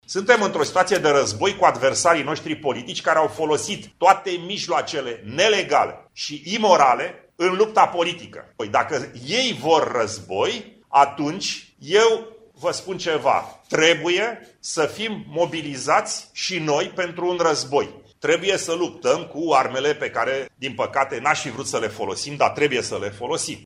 Declarații ale liderului ALDE, la deschiderea Școlii Politice de Vară